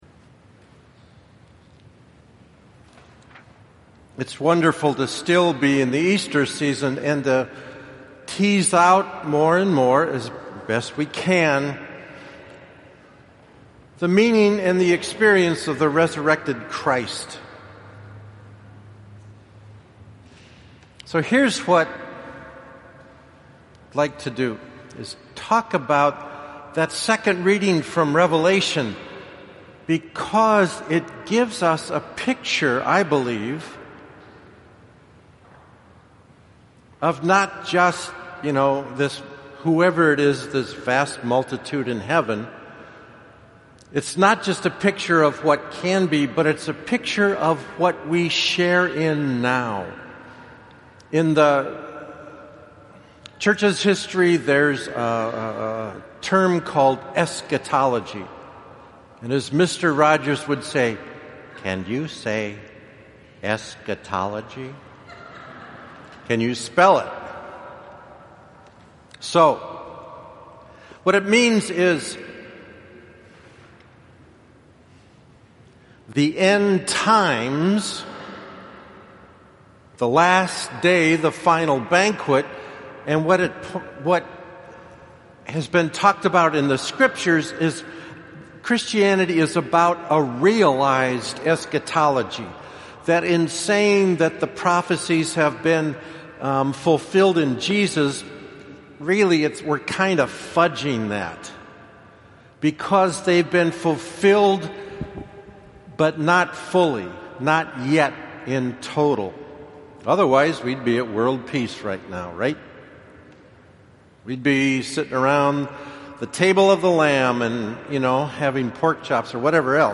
Homily for 4th Sunday of Easter 2016